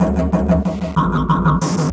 Percussion 04.wav